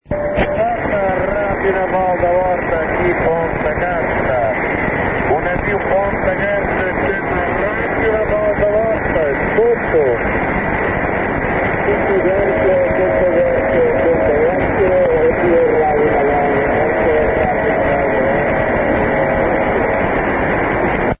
listen here to a radio contact between the portuguese naval stations Ponta Delgada and Horta, both Azores Islands